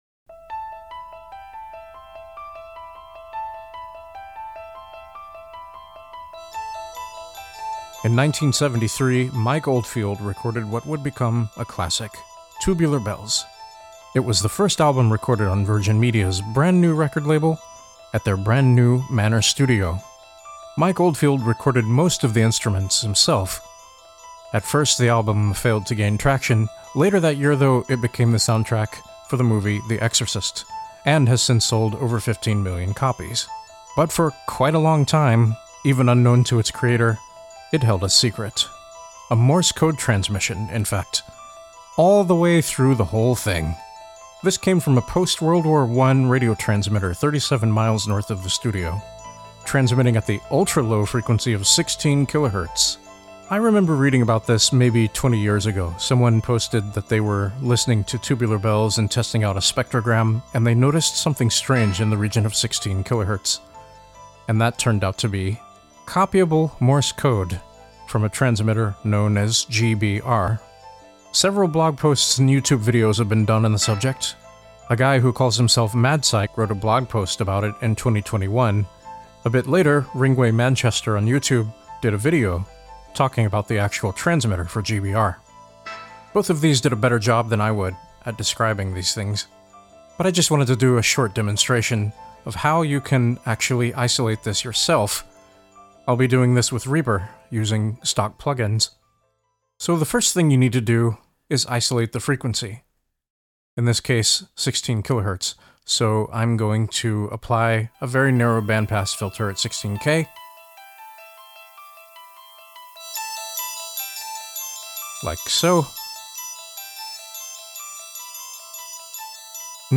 It's centered at 16 kHz, and so low in the mix that you can't actually hear it... at least, not without some help.
This is a very short, not particularly comprehensive demo using two different methods -- a pair of stock Reaper plugins and an SDR package to mostly isolate this morse transmission, which is heard throughout the entire album.